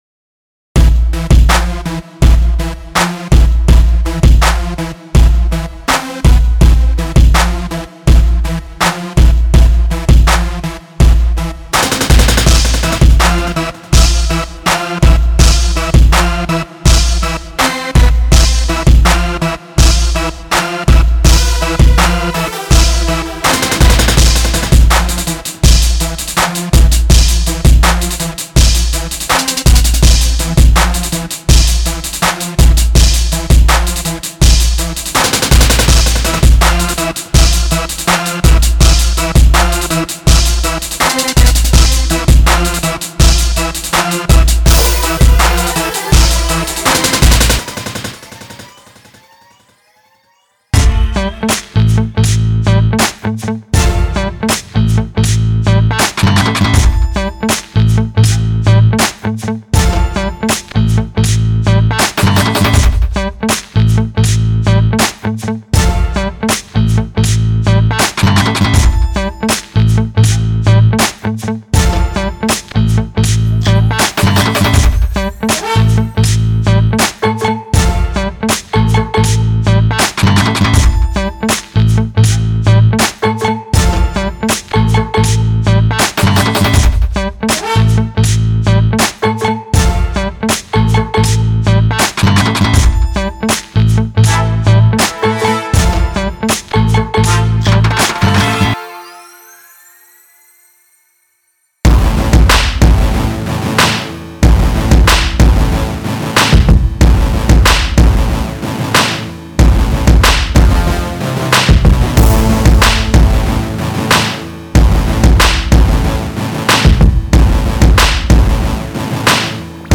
a bangin collection of five powerful Construction Kits